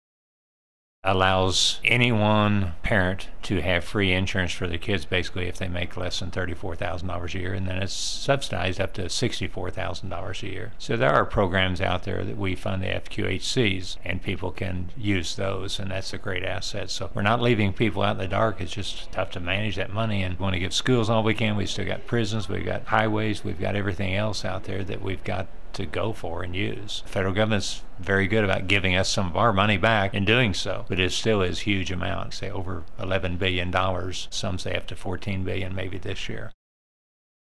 JEFFERSON CITY — State Sen. Mike Cunningham, R-Rogersville, discusses Medicaid funding and its relation to Missouri’s operating budget.